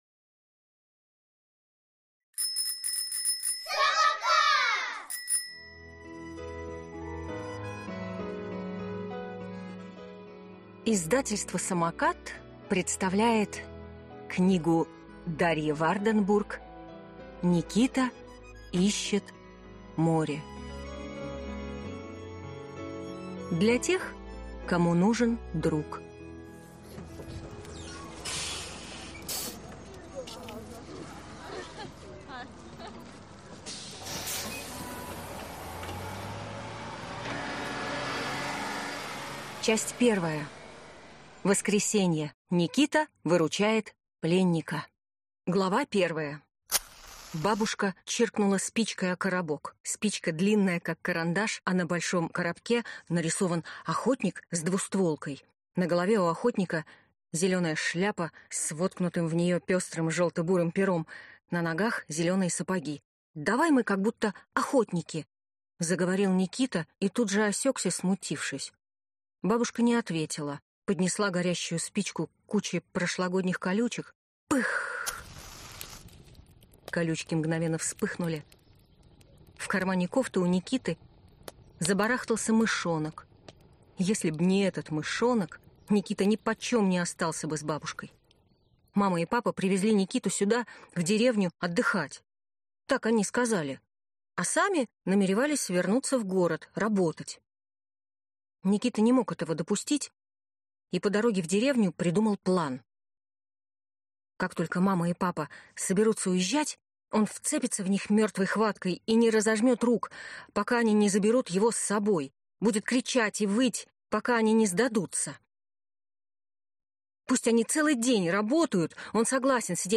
Аудиокнига Никита ищет море | Библиотека аудиокниг